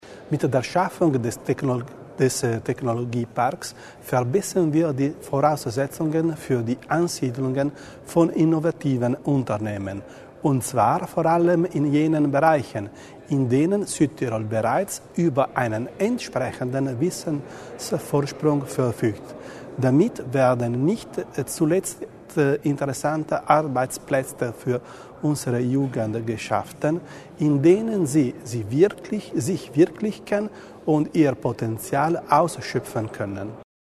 Landesrat Roberto Bizzo hat heute (28. Juli) im Gespräch zur Halbzeit der Legislaturperiode seine Vorstellungen einer innovativen Zukunft vorgestellt und auch gleich erläutert, wie diese Flut ausgelöst werden soll: durch den Technologiepark und das Innovations-Festival.
Im ehemaligen Alumix-Gebäude in Bozen Süd, also dort, wo das Herz des Technologieparks entstehen wird, hat Landesrat Bizzo heute einen Ausblick auf die zweite Hälfte der Amtszeit der Landesregierung gewagt und dabei vor allem das Ankurbeln der Innovationskraft in Südtirols Wirtschaft in den Mittelpunkt gestellt.